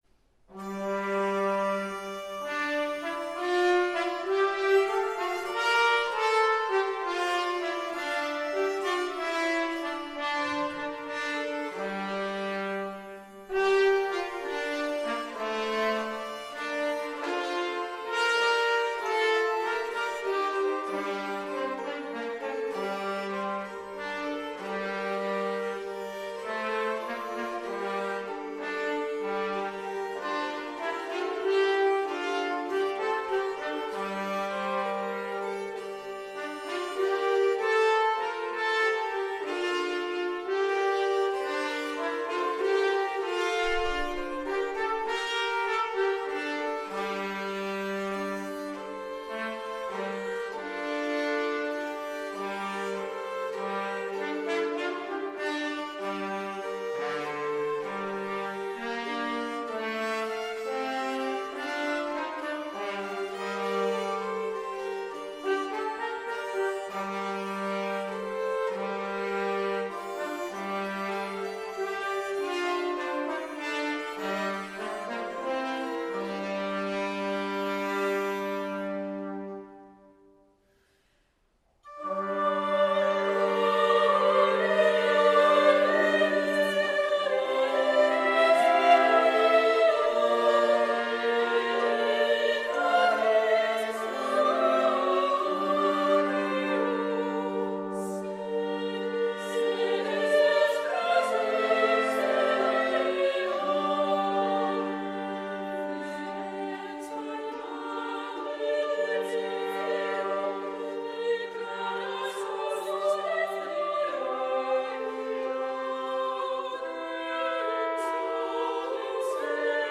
due trombe